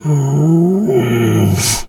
bear_roar_soft_13.wav